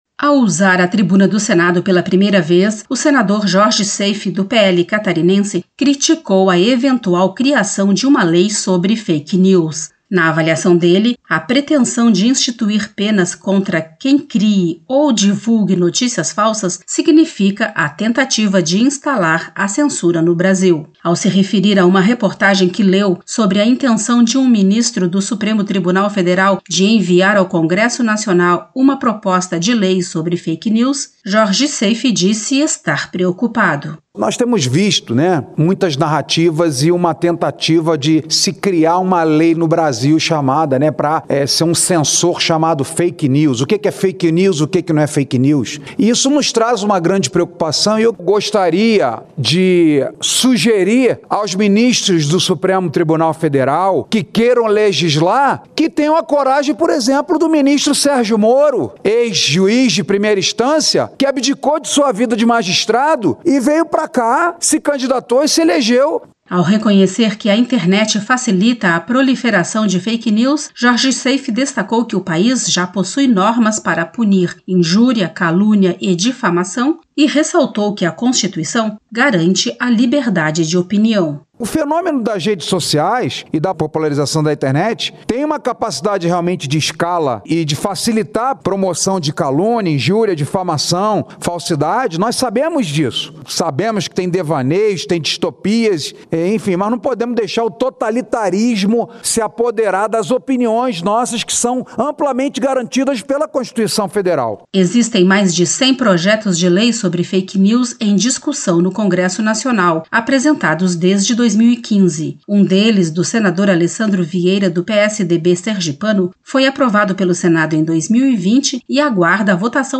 Discurso